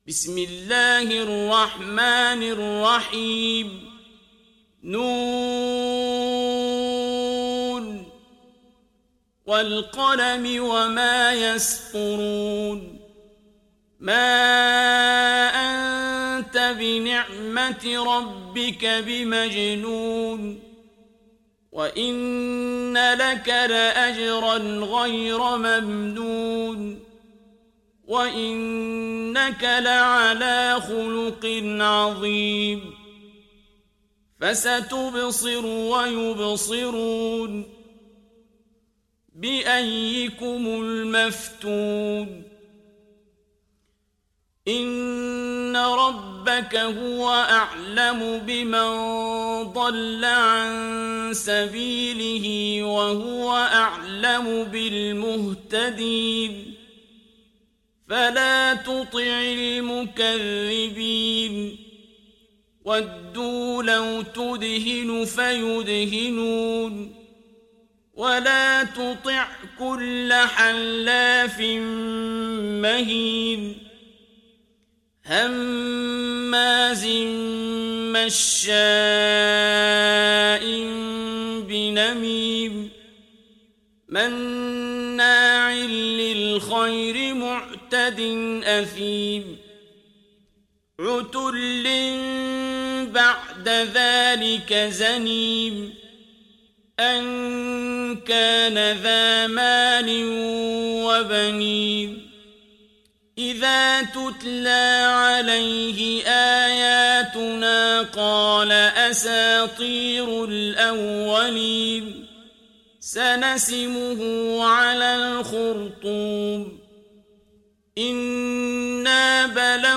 Sourate Al Qalam Télécharger mp3 Abdul Basit Abd Alsamad Riwayat Hafs an Assim, Téléchargez le Coran et écoutez les liens directs complets mp3